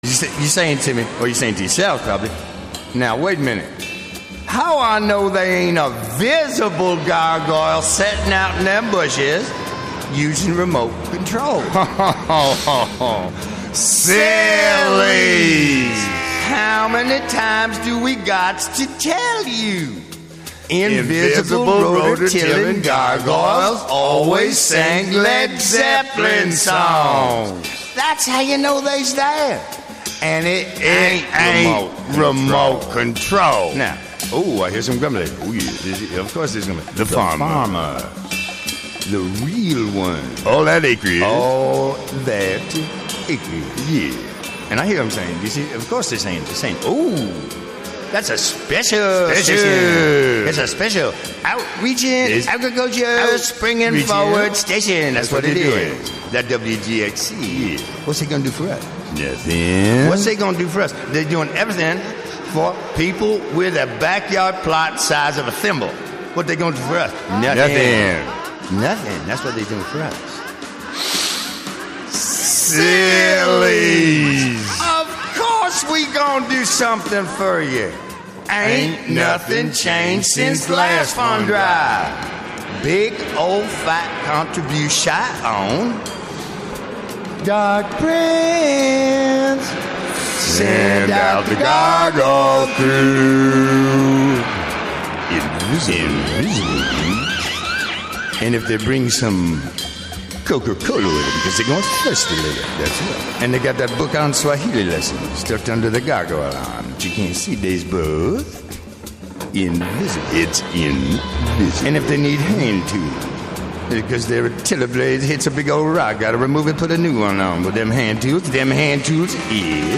Broadcast live from the Carnegie Room of the Catskill Library.